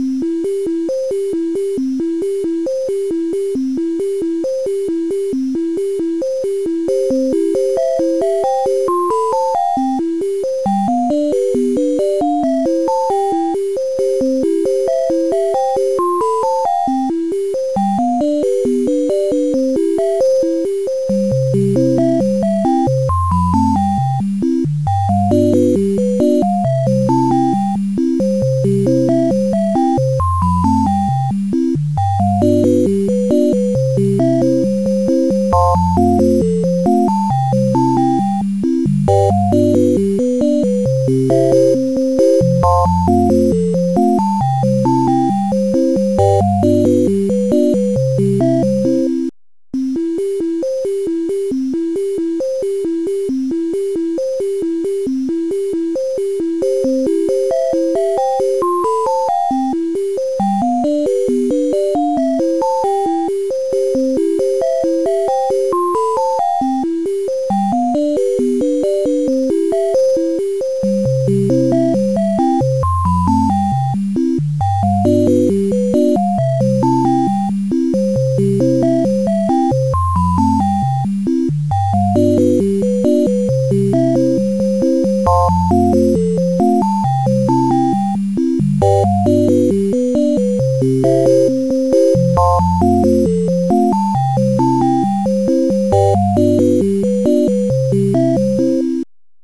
8bit Microcontroller MIDI Renderer
MIDIs converted using SimHC12 & MIDI Juke Box